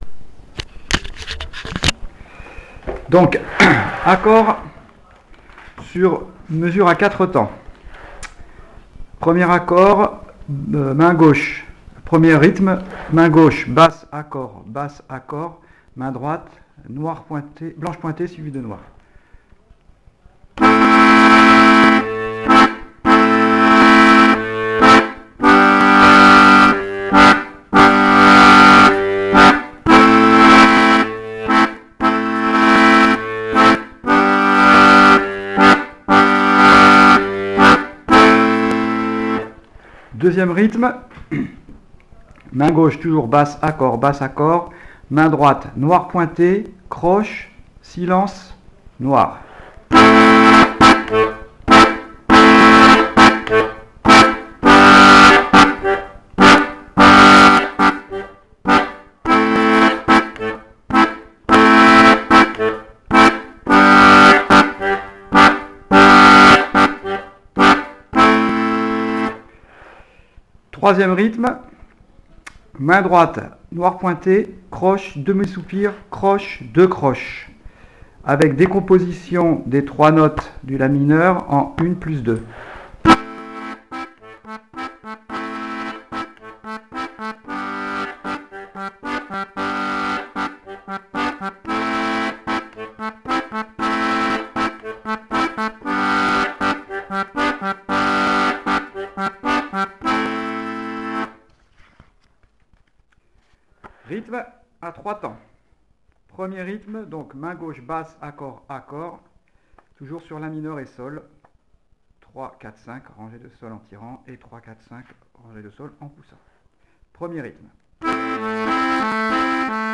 l'atelier d'accordéon diatonique
Rythmes 4 temps main droite main gauche:
Rythmes 3 temps main droite main gauche